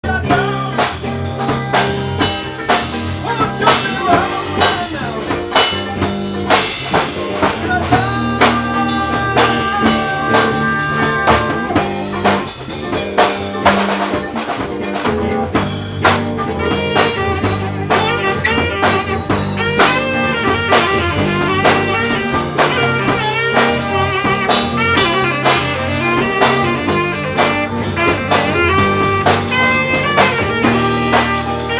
Enregistrement mini-disc (29.12.2001)